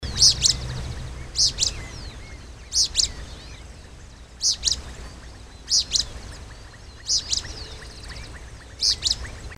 Uí-pi (Synallaxis albescens)
Nome em Inglês: Pale-breasted Spinetail
Localidade ou área protegida: Reserva Natural del Pilar
Condição: Selvagem
Certeza: Gravado Vocal
Synallaxisalbescens.mp3